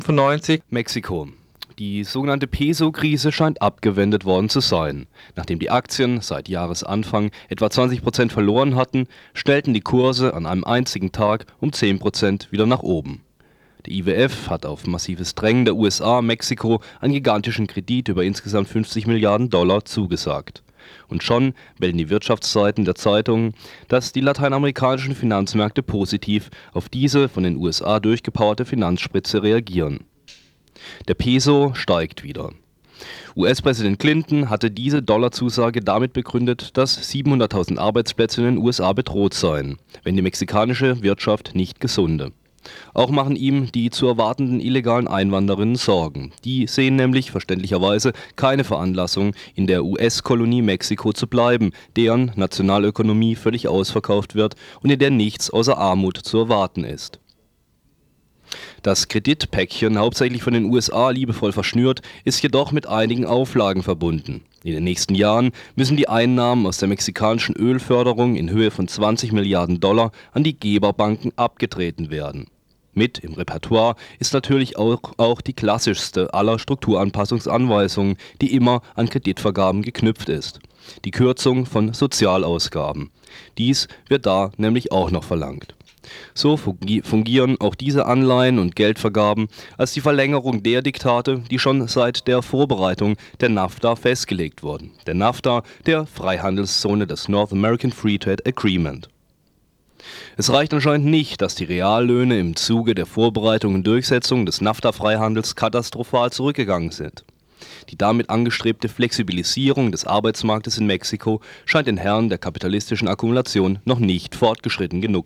Mexikos Ökonomie nach der IWF/USA-Dollarzusage. Ein Kommentar